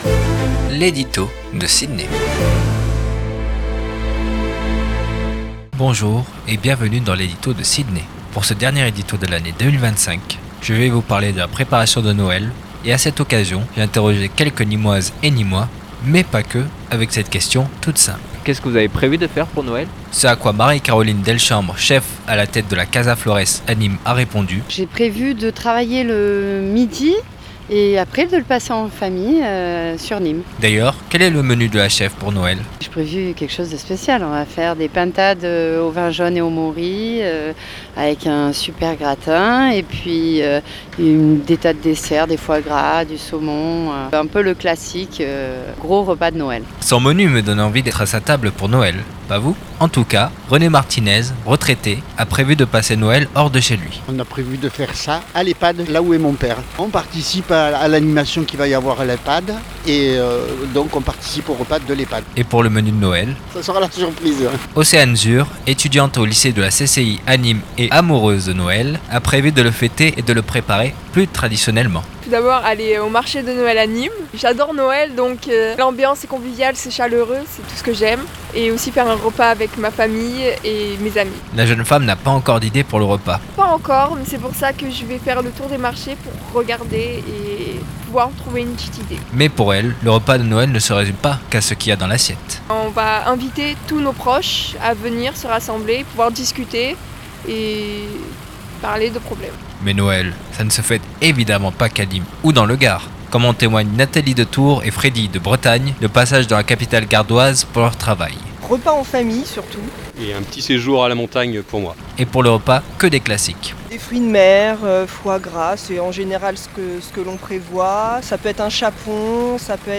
Il a pour cela interrogé quelques nîmoises et nîmois, mais pas que sur ce qu'elles et ils font pour les fêtes.